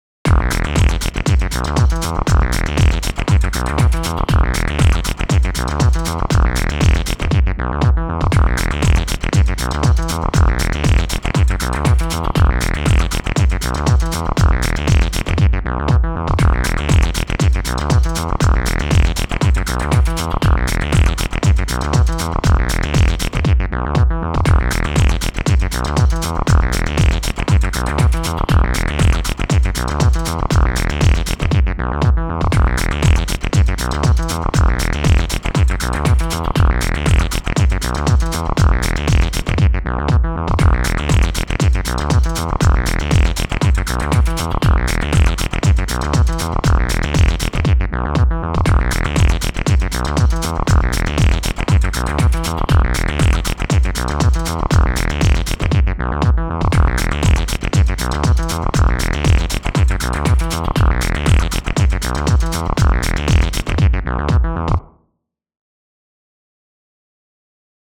• Q1：SynthBass
各製品からTB-303ライクなプリセットを探して使用。エフェクトはプリセットで掛けられているものをそのまま使用しており、製品個別のエディットは行っていない。
ベースのフレーズはスタッフ所有のハードシンセに組み込まれていたものを使用しており、MIDIデータの調整などは一切行っていない。
リズムトラックにはSpectrasonics Stylus RMXを使用。クリッピングの防止のためマスターチャンネルにWAVES L2をインサートしている。スレッショルドを0dBに設定し、キックが鳴る瞬間にわずかにリミッティングがかかっているが、極力シンセベーストラックの印象が変わらないようにしてある。
SynthBass-B
MI-New-WEB-Quiz-SynthBass_2.m4a